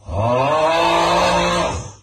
snore-2.ogg